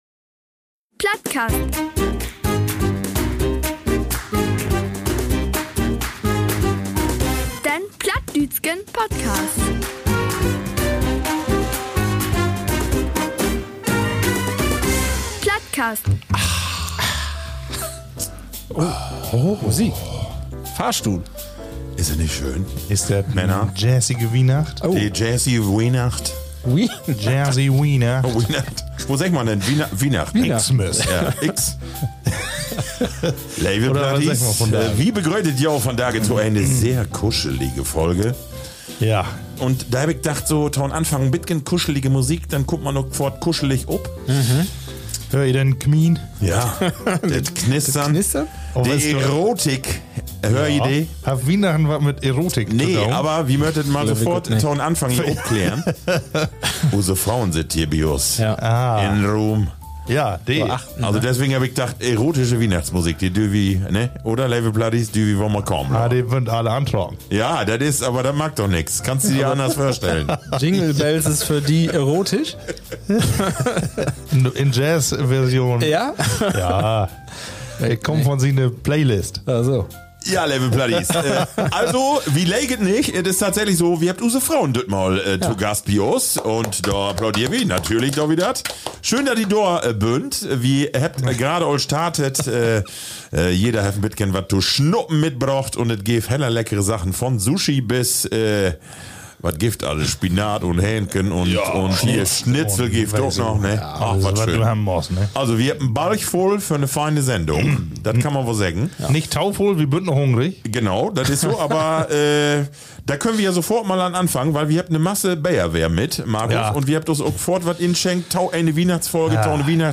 Wie in jedem Jahr, feiern die drei Plattbarden eine kleine Weihnachtsfeier mit Jahresrückblick. Und in diesem Jahr haben wir unsere Frauen dazu geladen.